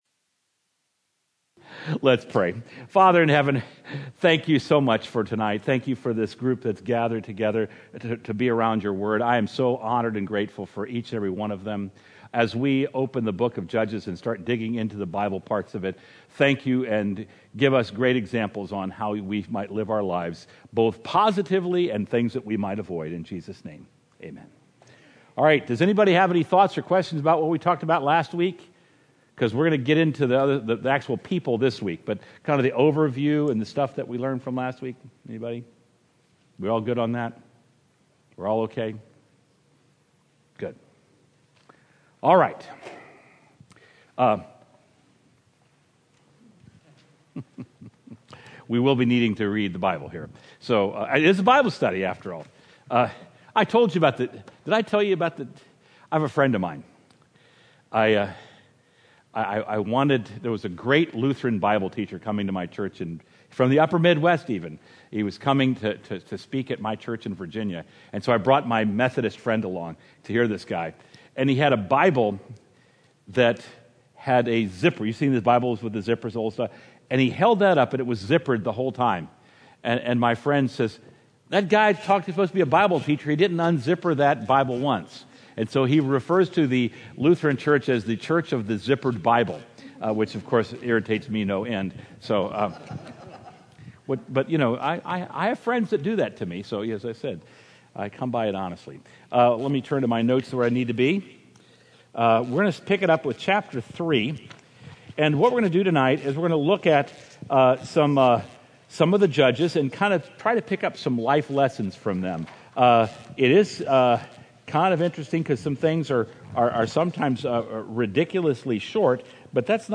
Experience the Word Bible Study